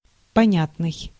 Ääntäminen
IPA: [pɐˈnʲætnɨj]